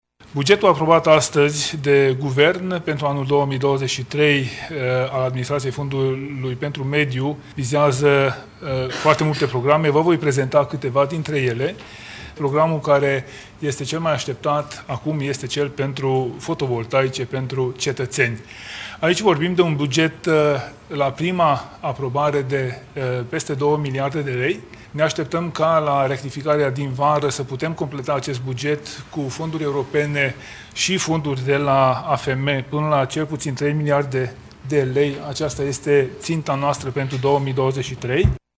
Anunțul a fost făcut de ministrul Mediului, Apelor şi Pădurilor, Tanczos Barna, azi (joi), la finalul şedinţei de Guvern.